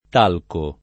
talco [ t # lko ] s. m.; pl. (raro) -chi